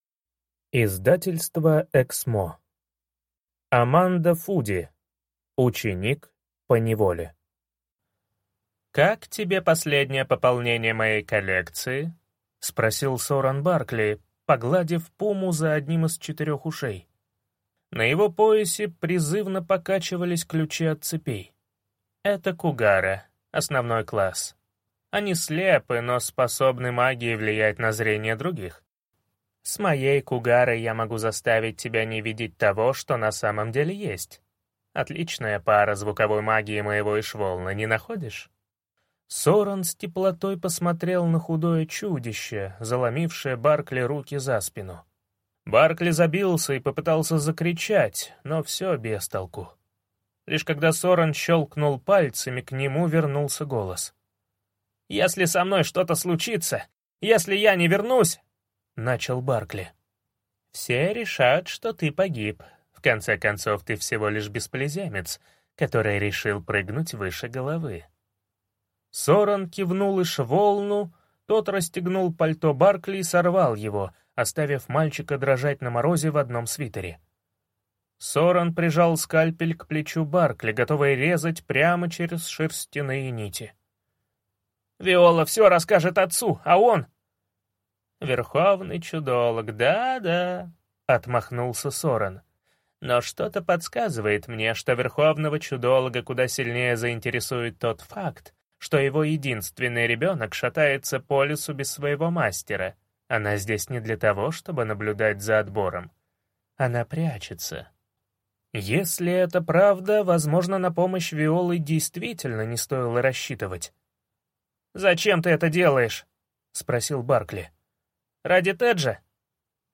Аудиокнига Ученик поневоле | Библиотека аудиокниг